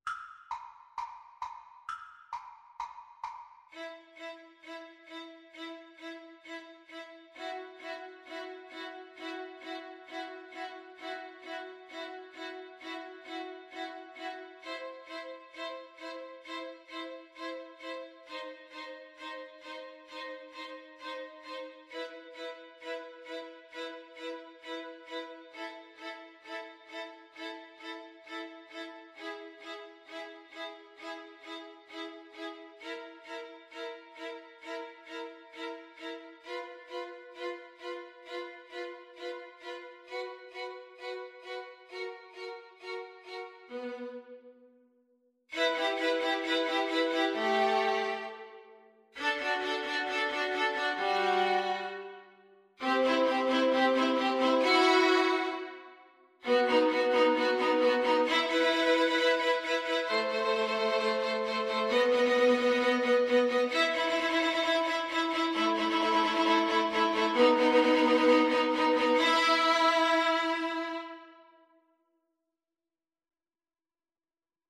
Allegro non molto =c.132 (View more music marked Allegro)
Violin Trio  (View more Easy Violin Trio Music)
Classical (View more Classical Violin Trio Music)